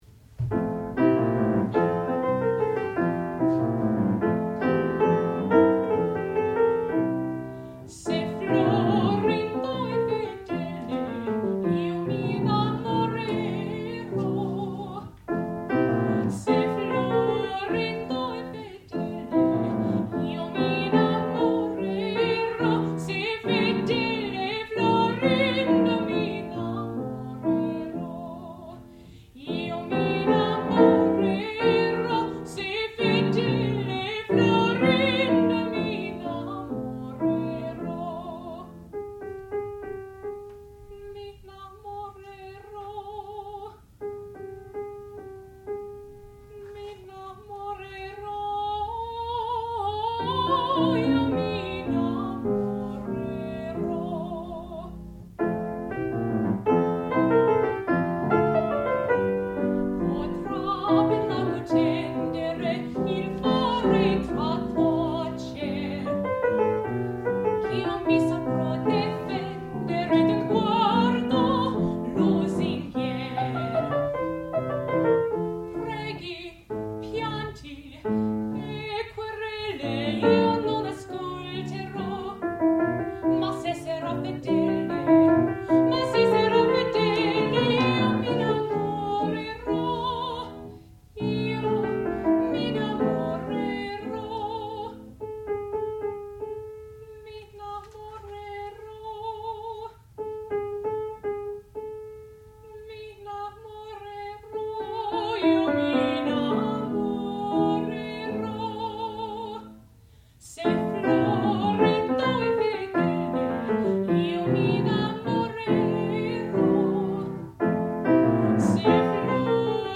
sound recording-musical
classical music
mezzo-soprano
piano
Qualifying Recital